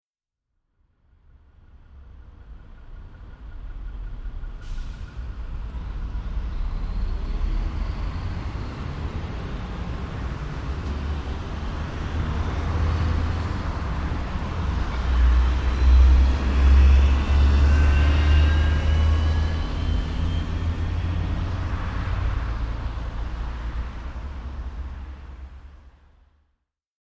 Eine kleine Hörprobe aus dem Garten eines Anwohners